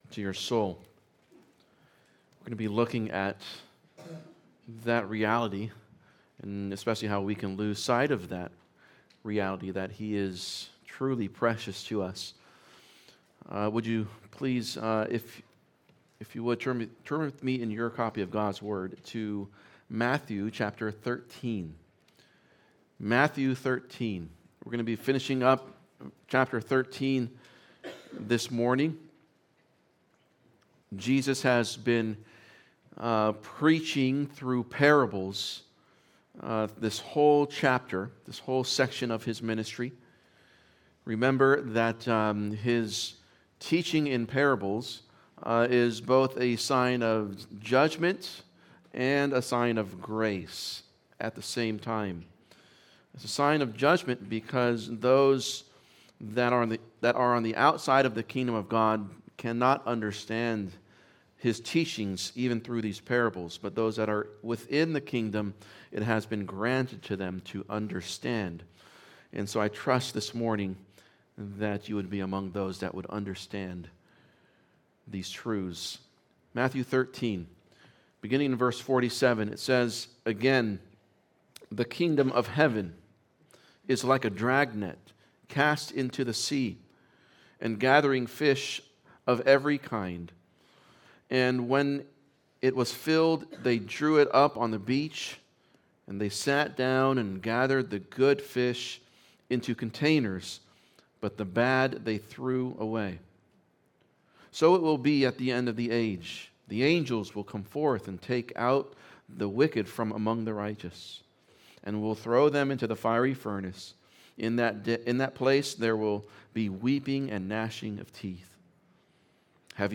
Sermon4.12.26.mp3